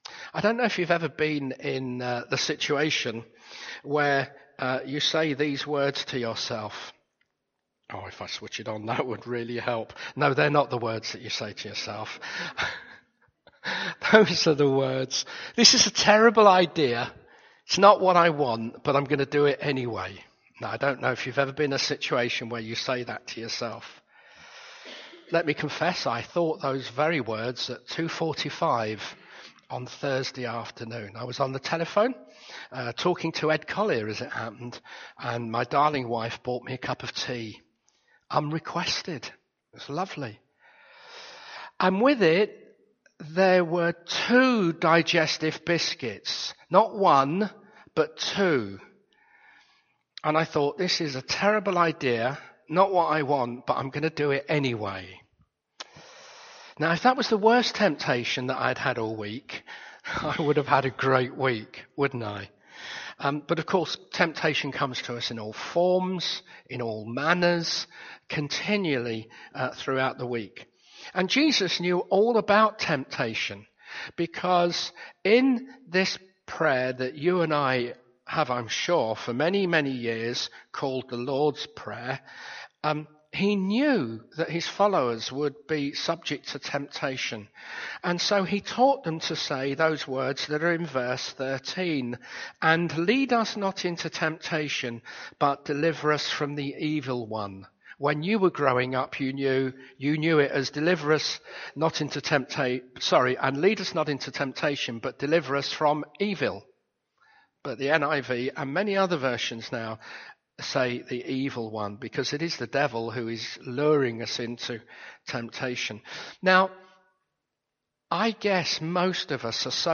Service Type: Afternoon Service